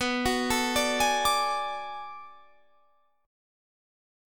Bm13 Chord
Listen to Bm13 strummed